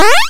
DoorOpen.wav